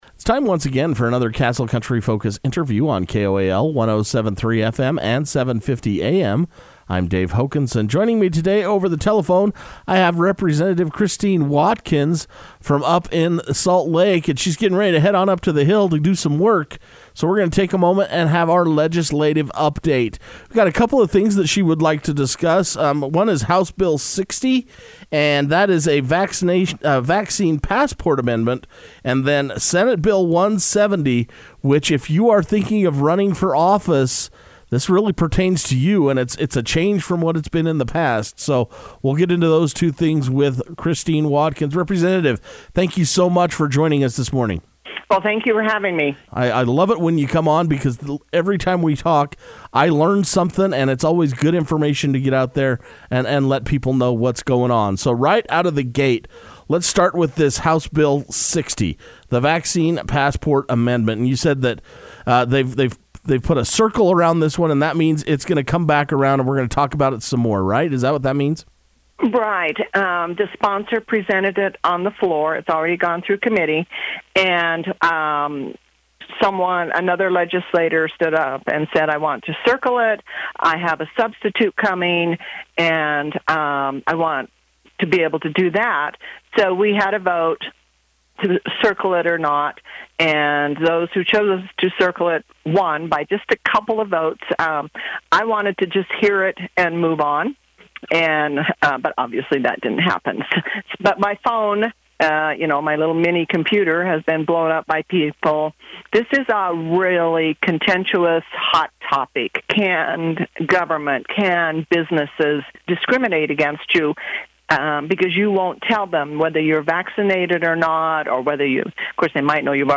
Individuals can listen to the entire interview with Representative Christine F. Watkins by clicking the link below